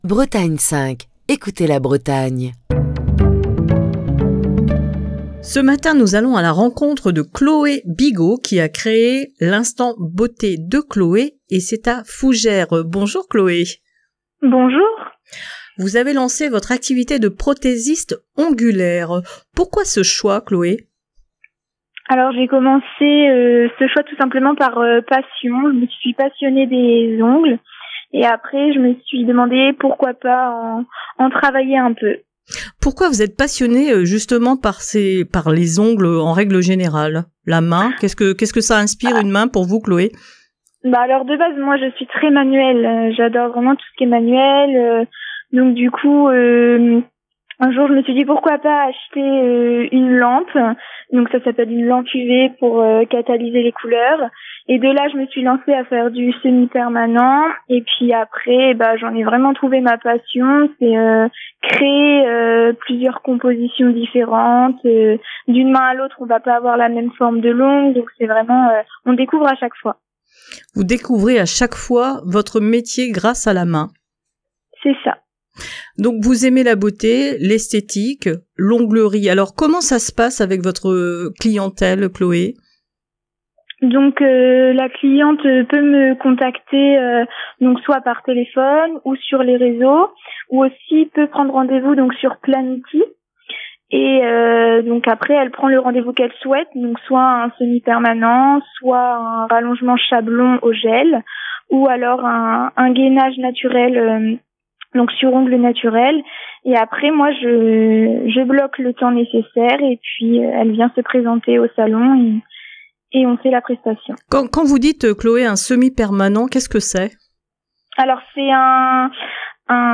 Pour ce dernier coup de fil du matin de la semaine